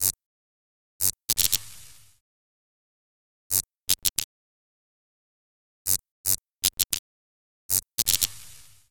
sfx_sparks_loop.wav